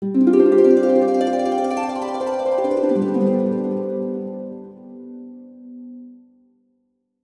Magical Harp (9).wav